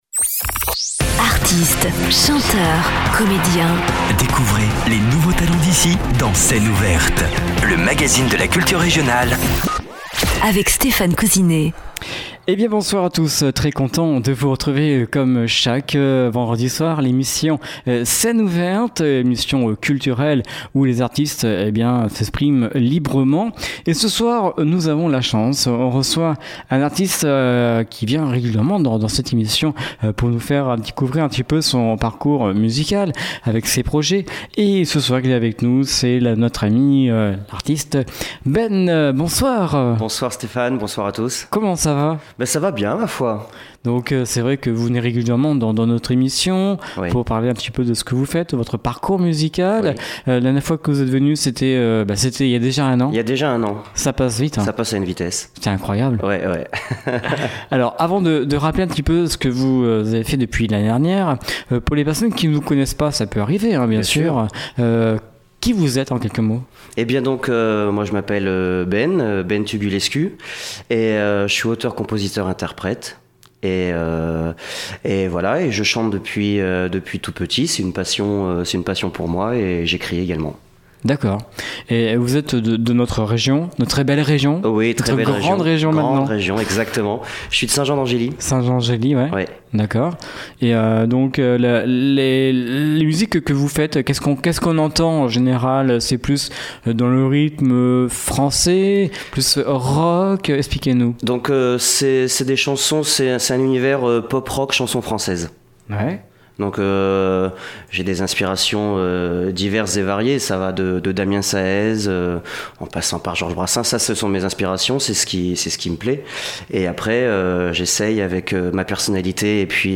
Une voix écorchée, un style Pop Rock Chanson française